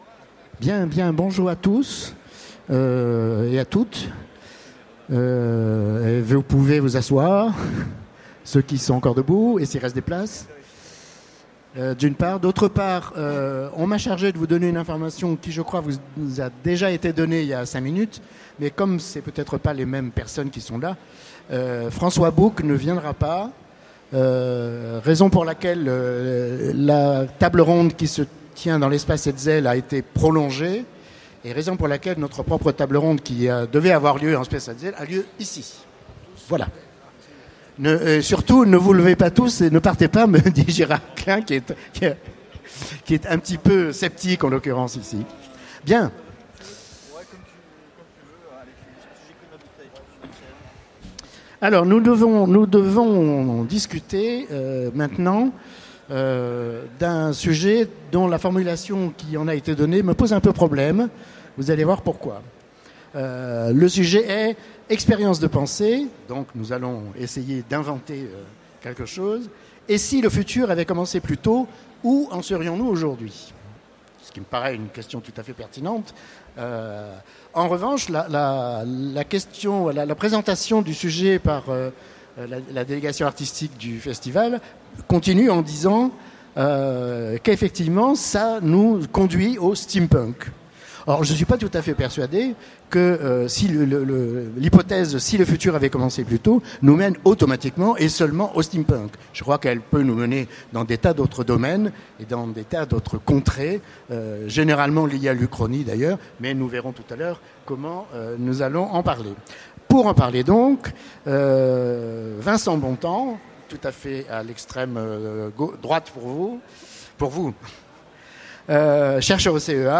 Utopiales 2015 : Conférence Et si le futur avait commencé plus tôt, où en serions-nous aujourd’hui ?